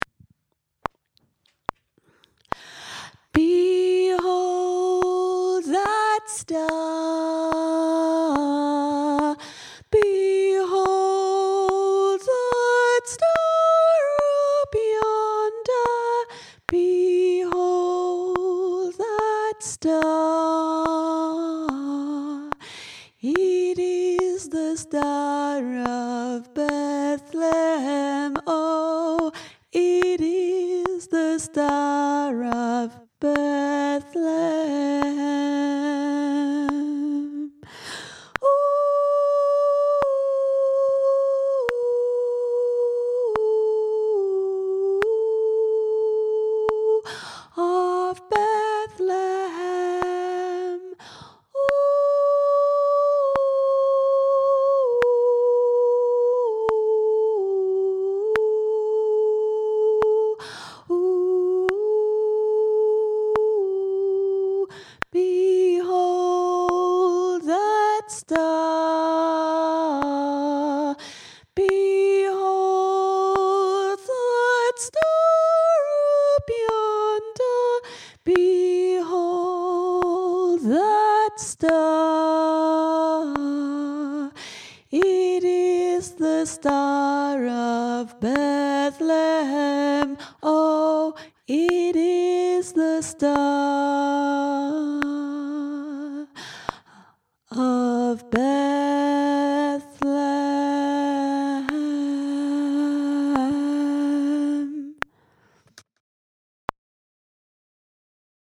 behold-that-star-soprano
behold-that-star-soprano.mp3